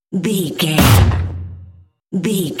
Dramatic stab wood hit
Sound Effects
Atonal
heavy
intense
dark
aggressive